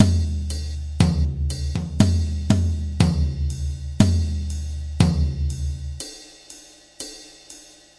tomtom ride 110bpm " tomtom ride 120bpm05
Tag: 击败 手鼓 120BPM 量化 节奏